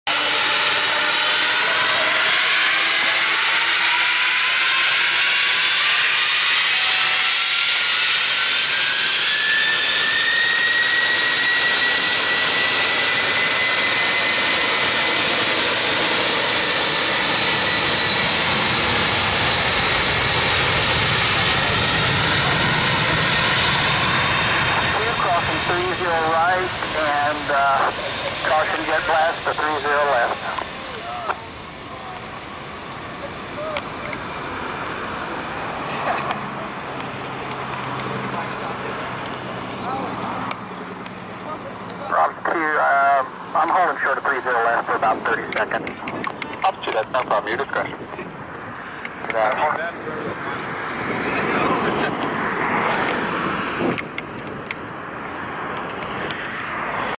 WOW what a sound this plane makes on takeoff! Being only a few hundred feet away from a 747 as it throttles up to takeoff power is such an unbelievable experience that I wish I could experience it again! This 747 was on a military charter out of SJC to Birmingham. ———————————— Plane: Boeing 747-422